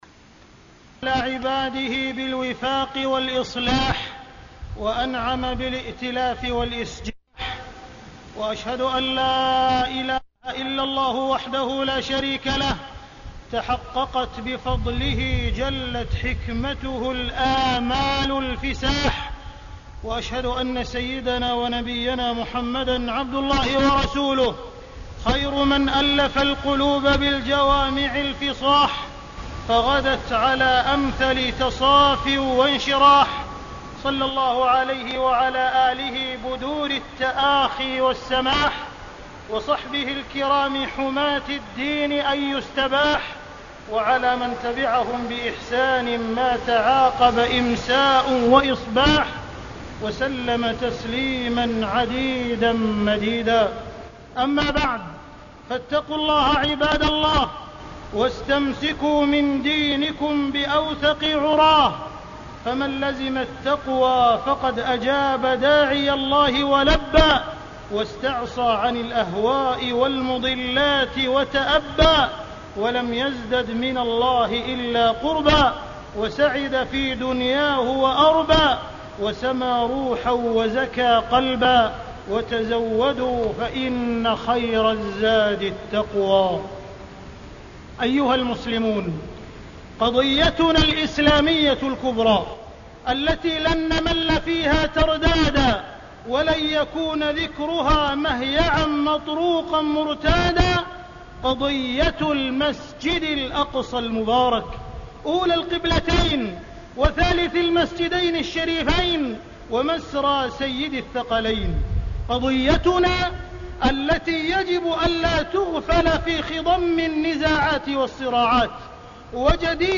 تاريخ النشر ٢٨ محرم ١٤٢٨ هـ المكان: المسجد الحرام الشيخ: معالي الشيخ أ.د. عبدالرحمن بن عبدالعزيز السديس معالي الشيخ أ.د. عبدالرحمن بن عبدالعزيز السديس وفاق مكة المكرمة